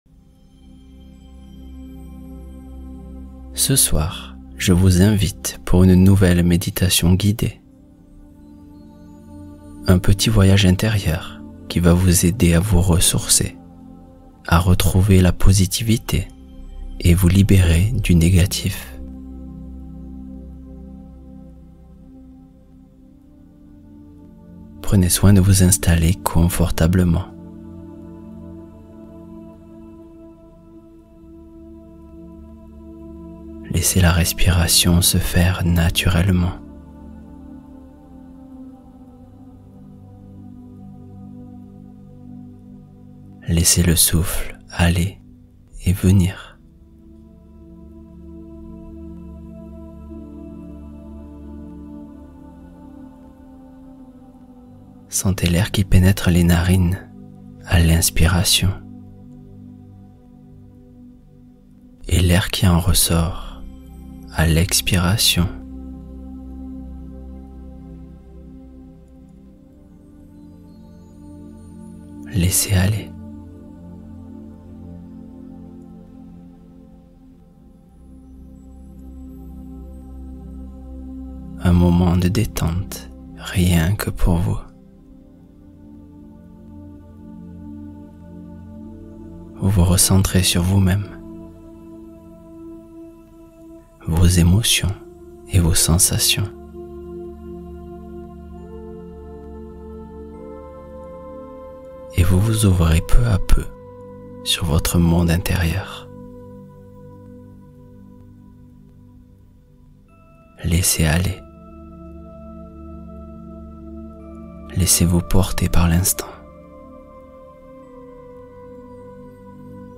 Histoire du soir : les deux princes et la montgolfière